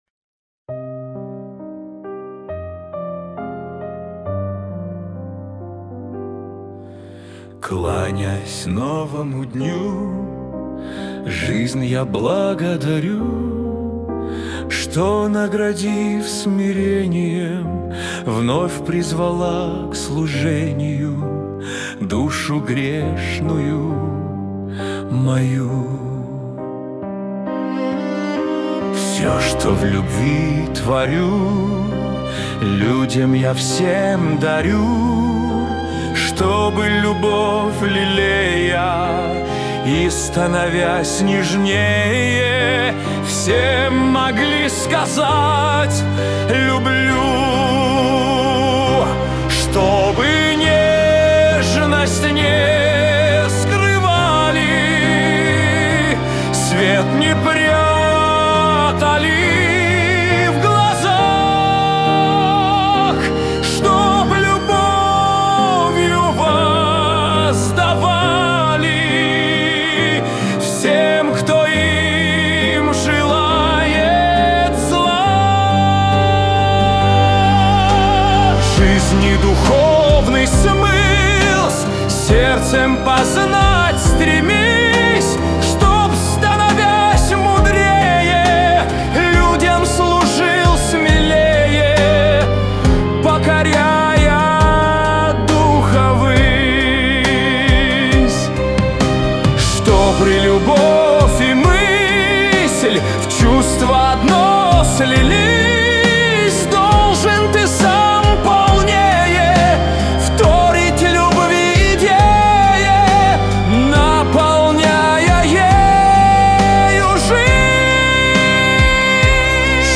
на мотив мальгашской народной песни
Для Медитаций